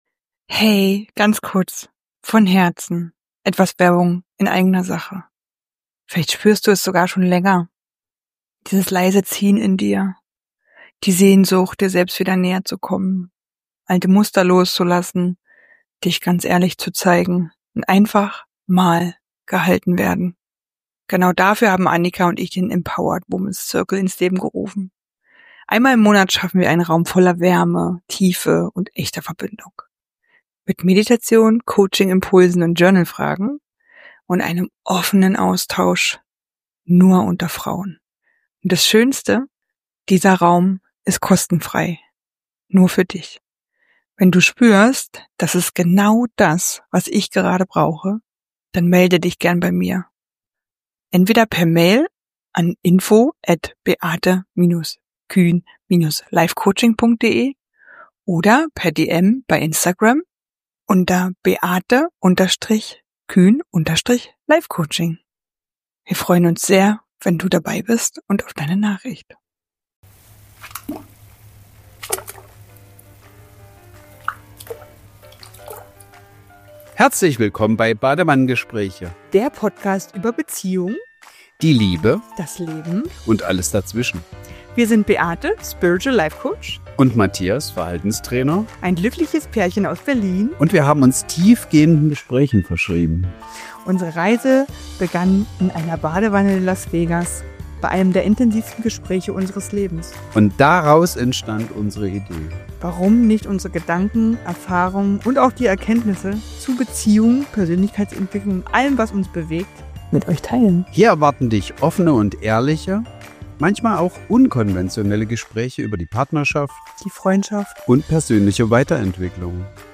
Die Wärme des Sommers bewahren – Eine geführte Meditation mit Handpan ~ Badewannengespräche Podcast